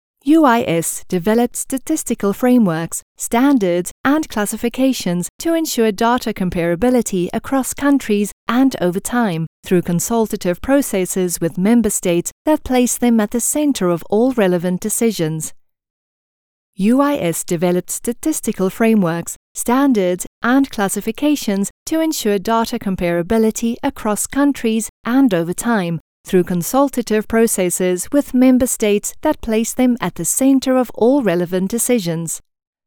Unternehmensvideos
Meine Stimme kann zugänglich und freundlich, bestimmend, warm und glaubwürdig oder auch schrullig und lebhaft sein.
Schallisolierter Raum
HochMezzosopran